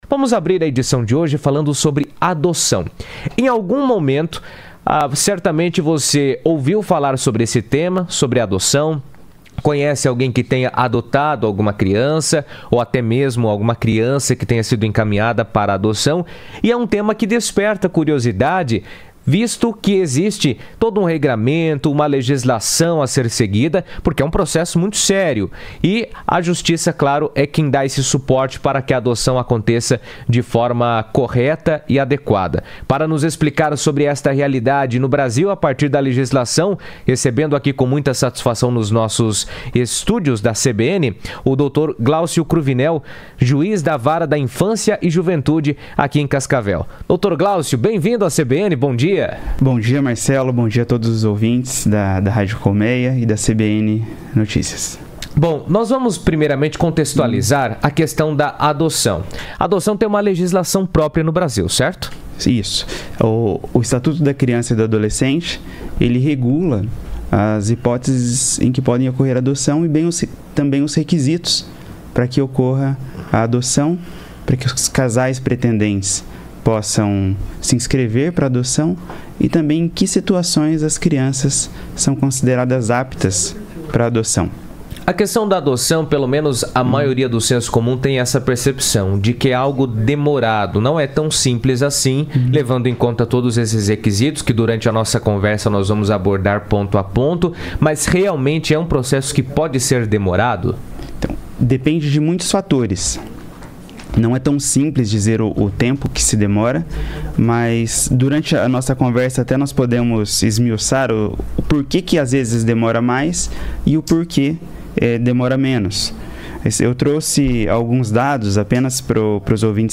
O processo de adoção de crianças envolve diversas etapas legais, desde o cadastro dos pretendentes até a avaliação da família e a decisão judicial definitiva. Em entrevista à CBN, o Dr. Glaucio Cruvinel, juiz da Vara da Infância e Juventude de Cascavel, explicou como funcionam essas etapas e destacou a importância de seguir corretamente os procedimentos para garantir a segurança e o bem-estar das crianças.